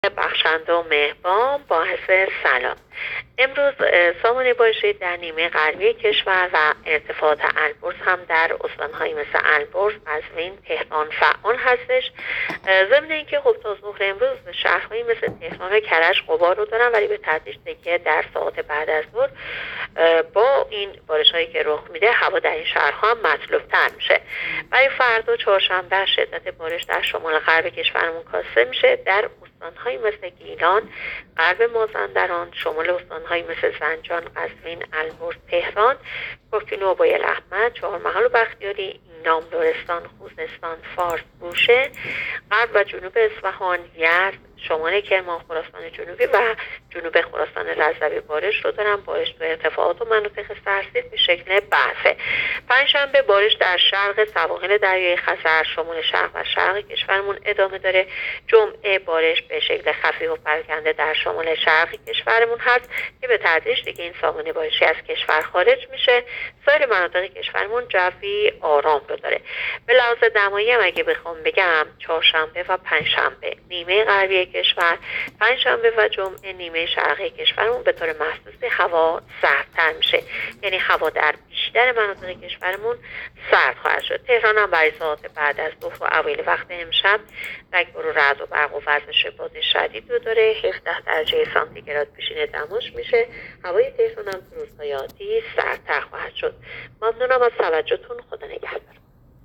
گزارش رادیو اینترنتی پایگاه‌ خبری از آخرین وضعیت آب‌وهوای ۶ آذر؛